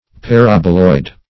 Paraboloid \Pa*rab"o*loid\ (-loid), n. [Parabola + -oid: cf. F.